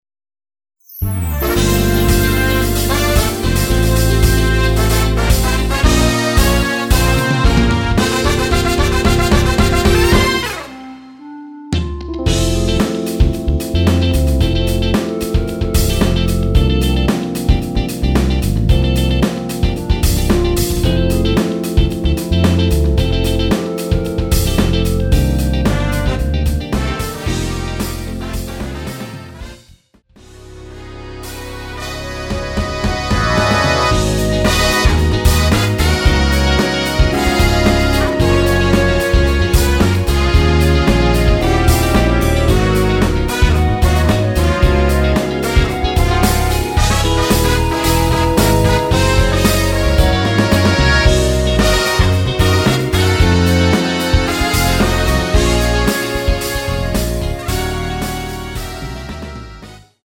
원키에서(-3)내린 멜로디 포함된 MR입니다.
멜로디 MR이라고 합니다.
앞부분30초, 뒷부분30초씩 편집해서 올려 드리고 있습니다.
중간에 음이 끈어지고 다시 나오는 이유는